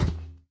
irongolem
walk4.ogg